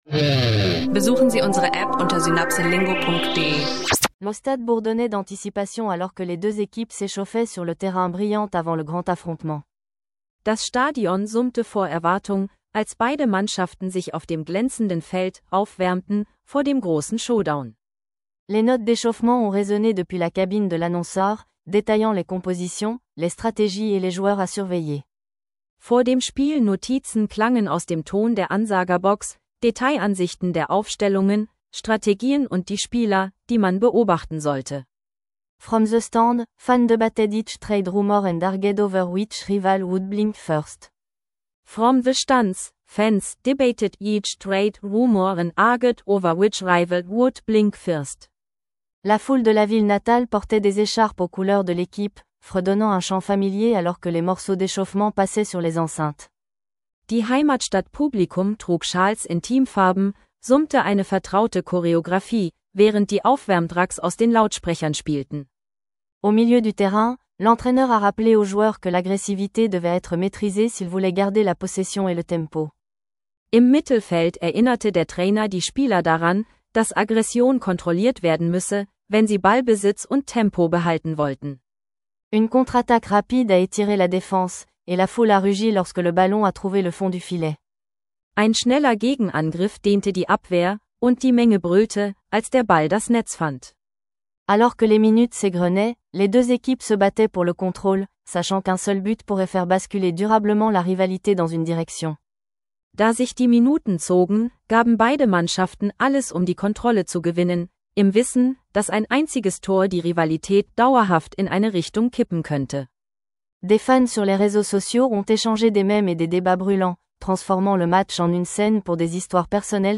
Eine mitreißende Französisch-Lektion über Sport, Rivalitäten und Fanperspektiven – ideal für Anfänger und Fortgeschrittene